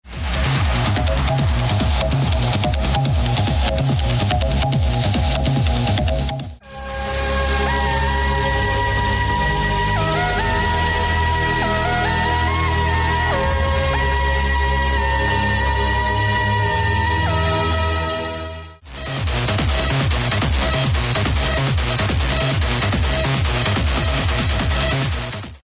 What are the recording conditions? towards the end of his set